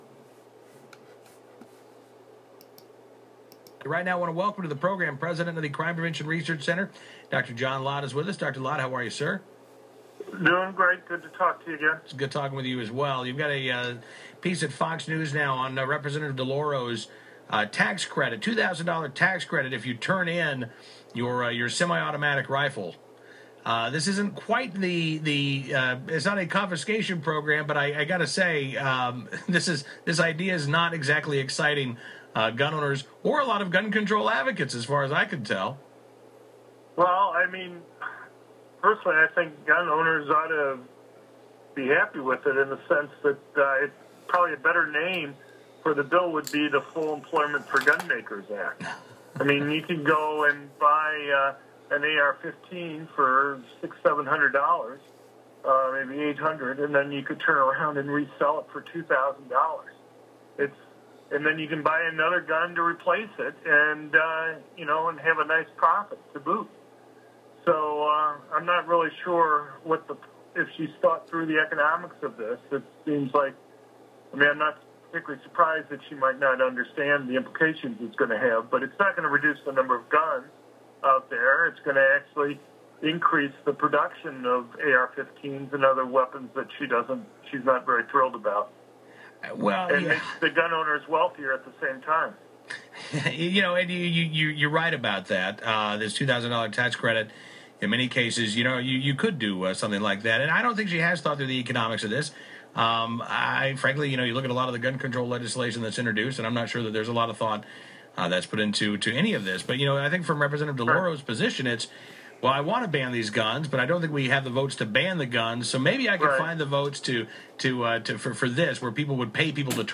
media appearance
John Lott talked to the Sportsman Channel 5:15 to 5:24 PM EDT on Friday, May 1st.